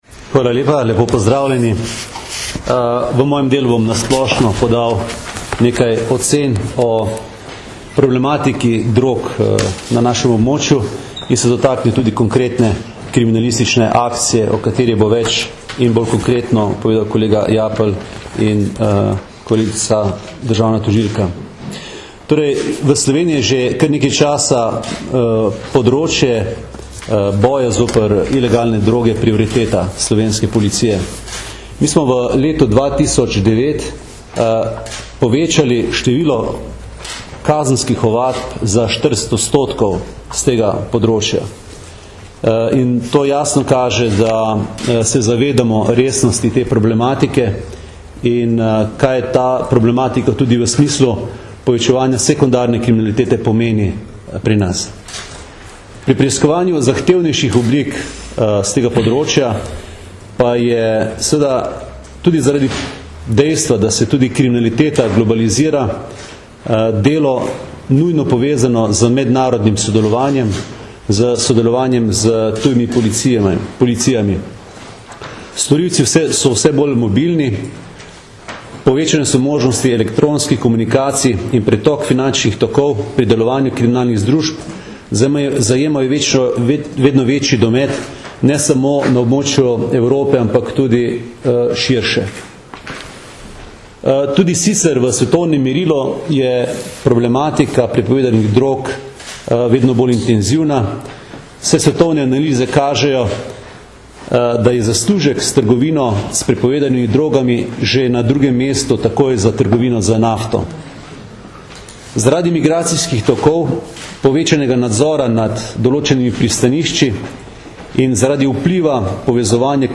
Predstavniki Policije in Vrhovnega državnega tožilstva Republike Slovenije smo na današnji skupni novinarski konferenci povedali več o nedavnem razkritju dobro organizirane mednarodne kriminalne združbe, ki je organizirala transporte kokaina iz Južne Amerike v Evropo.
Zvočni posnetek izjave mag. Aleksandra Jevška (mp3)
AleksanderJevsek.mp3